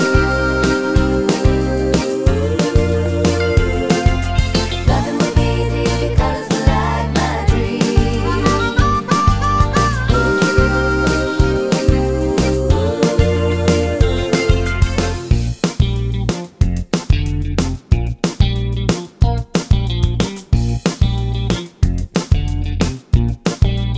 no harmonica Pop (1980s) 3:57 Buy £1.50